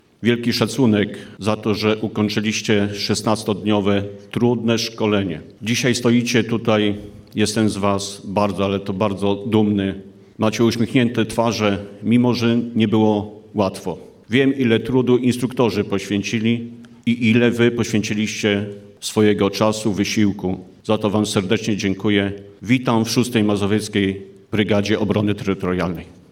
Oath Ceremony in Radom